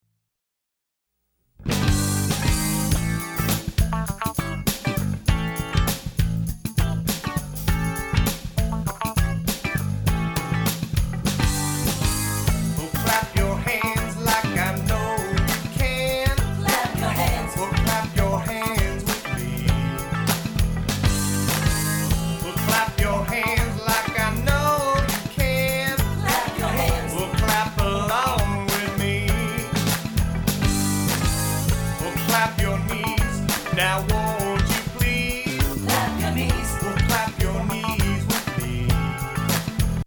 exciting and upbeat Rock, Pop and Funk